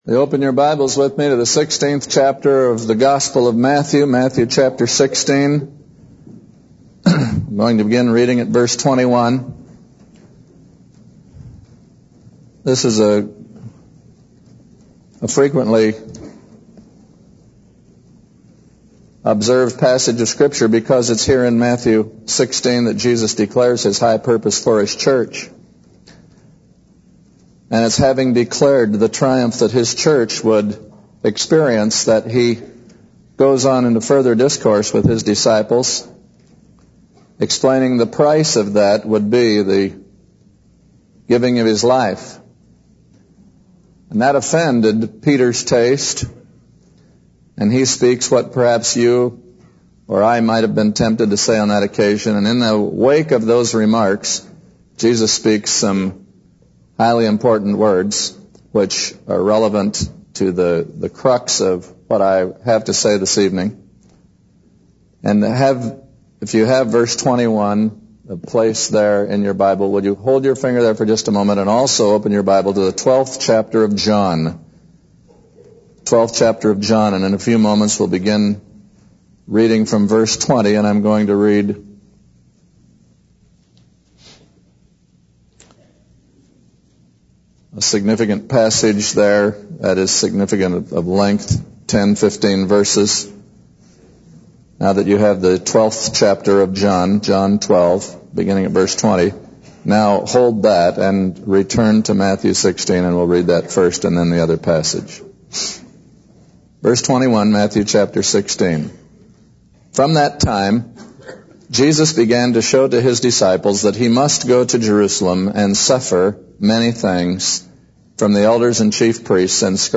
In this sermon, the preacher discusses the sin of suicide and its scriptural implications. He emphasizes that suicide is not an acceptable way to handle life's problems, as it goes against the teachings of the Bible.